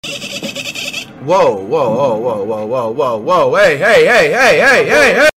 woah hey sound effects
woah-hey